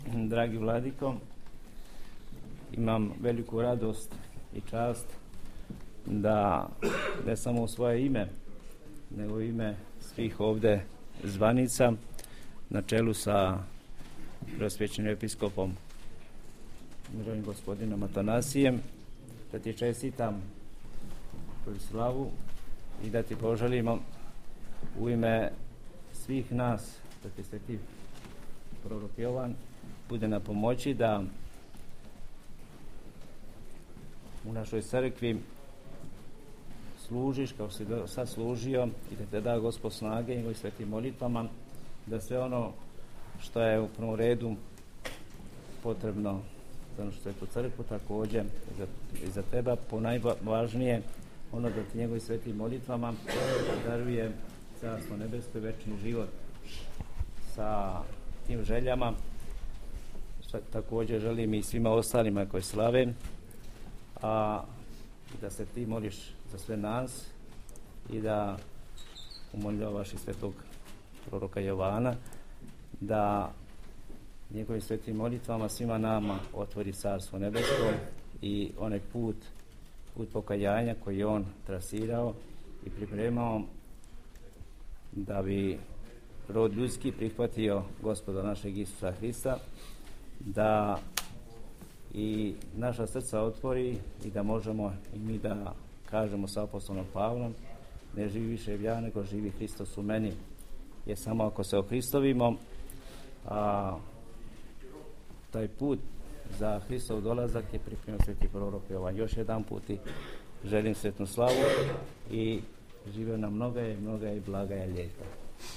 • Честитка Епископа Пахомија: